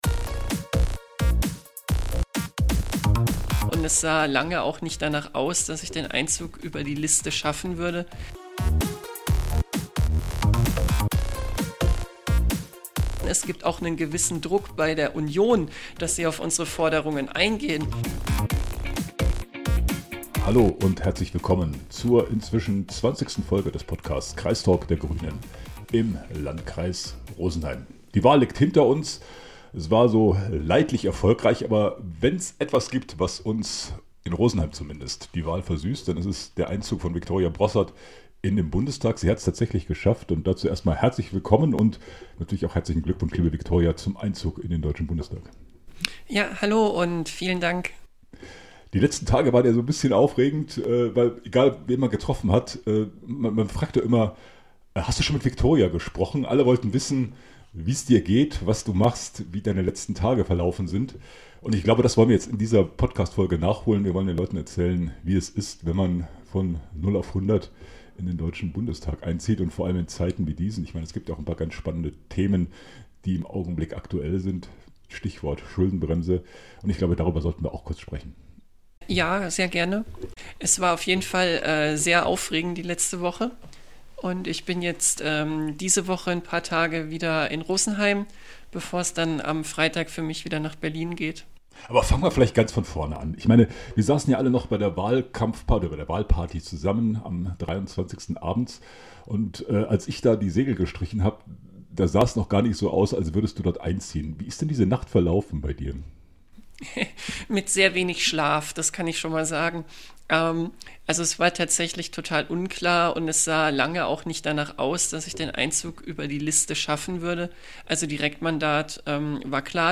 Talk mit Victoria Broßart über ihren Start in Berlin.